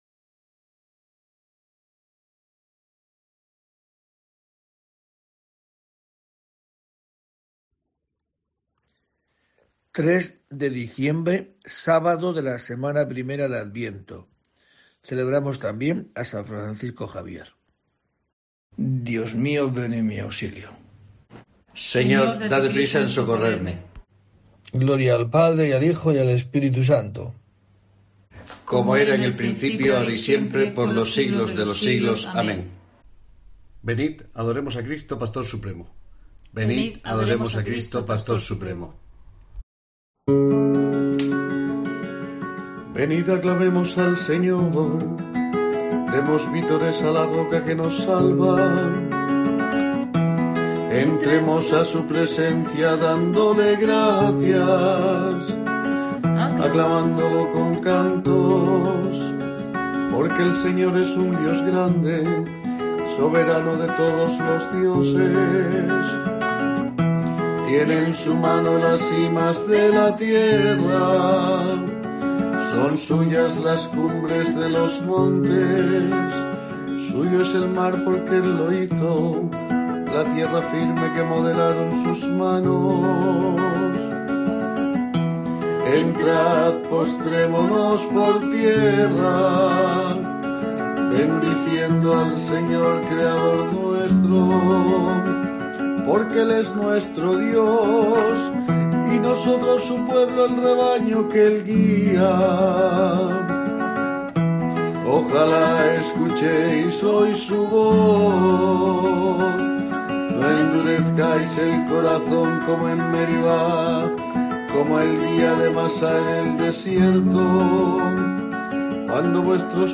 3 de diciembre: COPE te trae el rezo diario de los Laudes para acompañarte